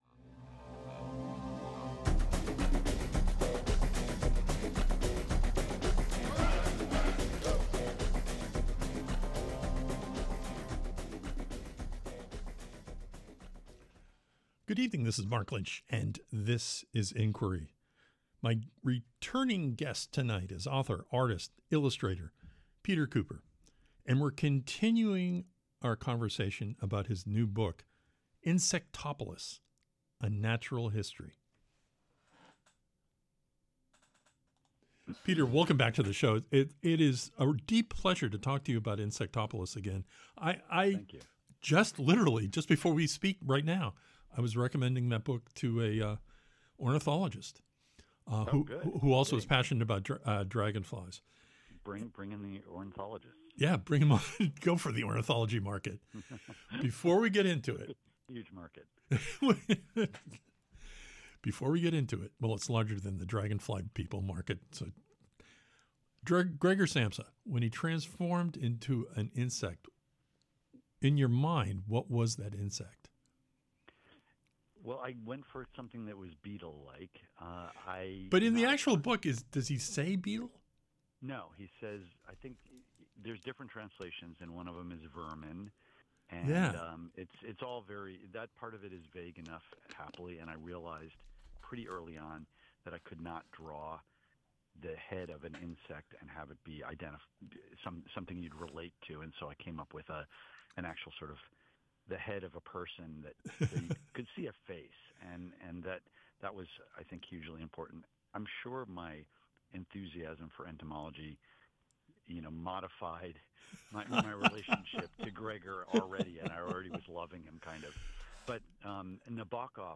Tonight on Inquiry, we continue our talk with author, artist, and illustrator PETER KUPER about his new stunning graphic history of insects, entomologists, and human history, INSECTOPOLIS: A NATURAL HISTORY. Tonight we’ll discuss the ghosts and talking statues in the book.